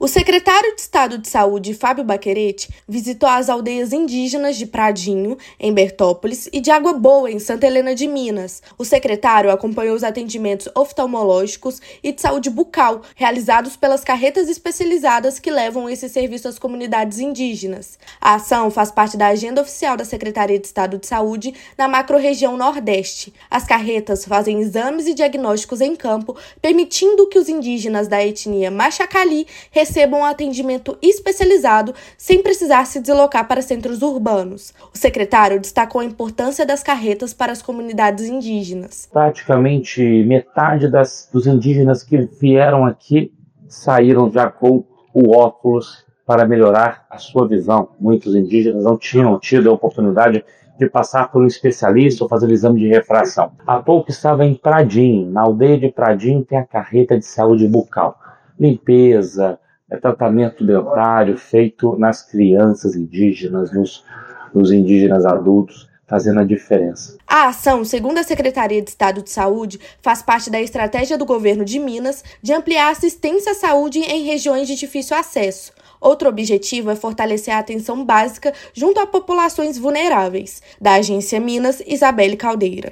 Iniciativas em Bertópolis e Santa Helena de Minas, que buscam levar a Saúde cada vez mais perto do cidadão, beneficiam agora também os povos originários no estado. Ouça matéria de rádio.